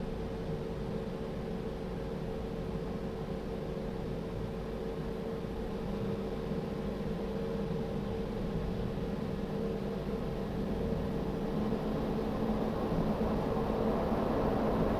Запись фрагмента работы блока питания Corsair AX1500i в гибридном режиме.